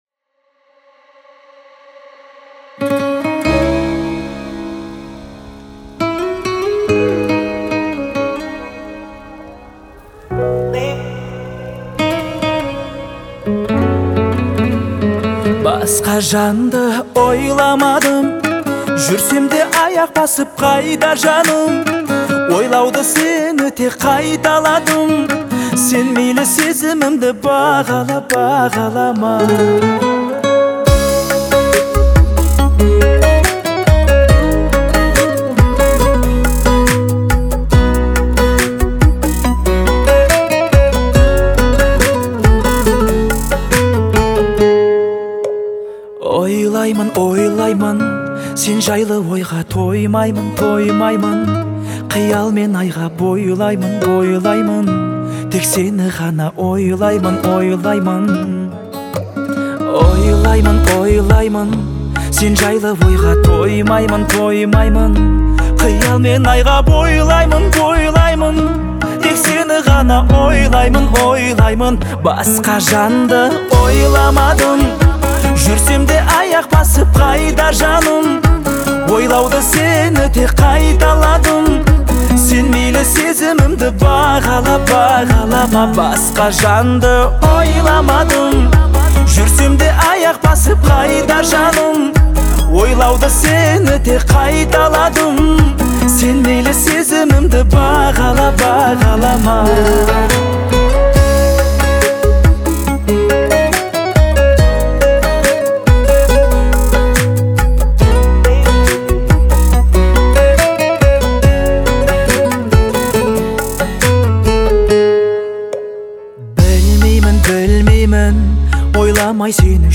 Музыка наполнена мягкими инструментальными аранжировками